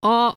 [o̞]
listen This is a pure o, unlike the English one, which is a diphthong. The tongue is kept lowered while pronouncing the Japanese o, and the lips are mostly kept from moving.